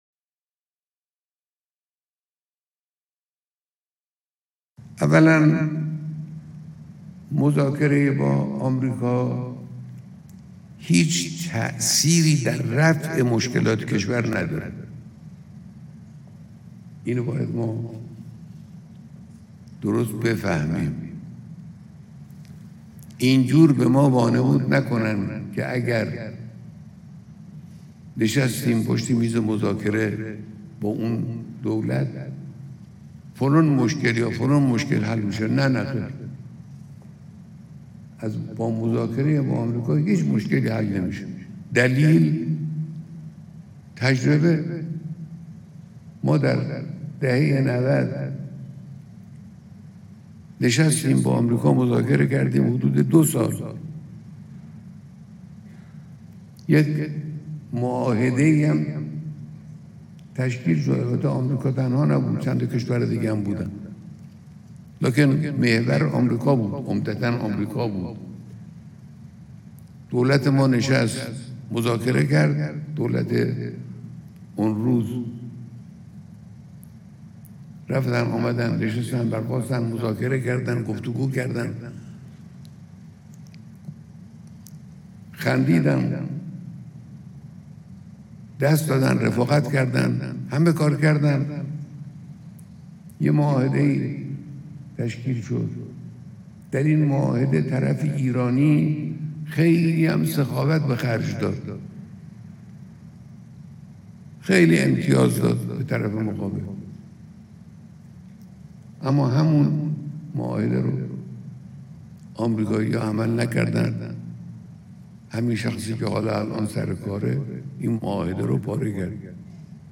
سخنان خامنه ای – مذاکره با آمریکا – شنیداری